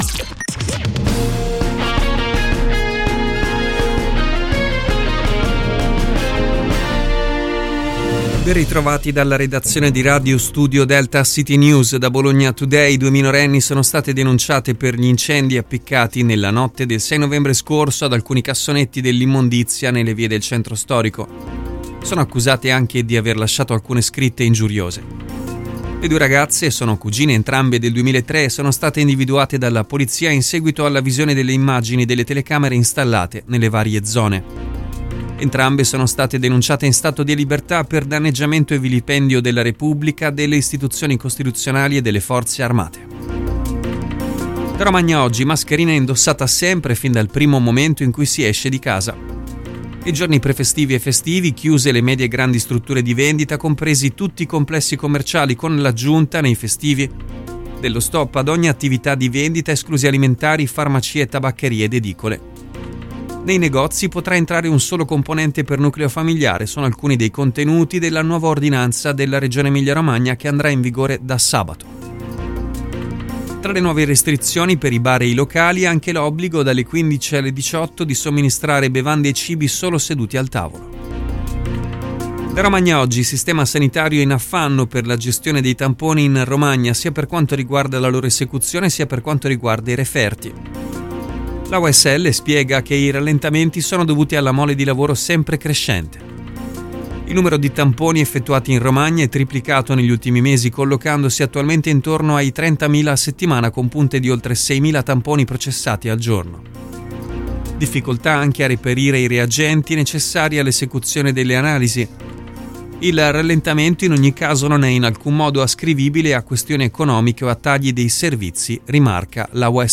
Ascolta le notizie locali di Radio Studio Delta in collaborazione con CityNews